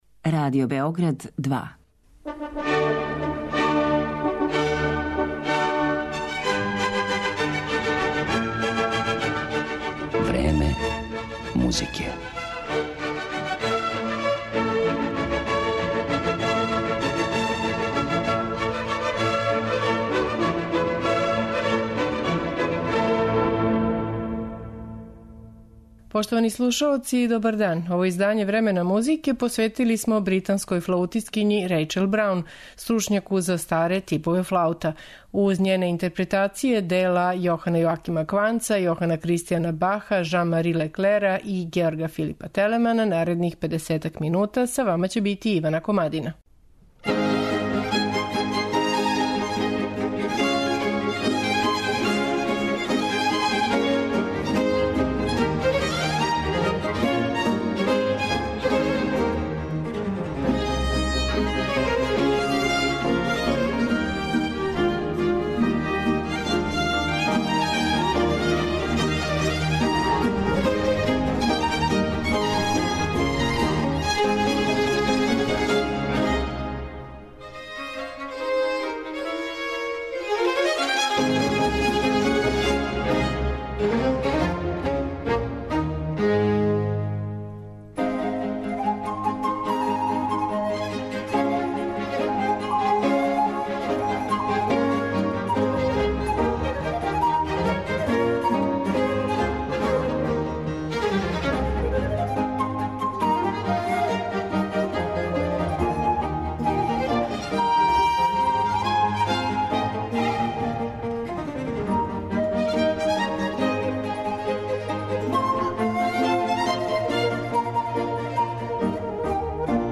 Портрет флаутисткиње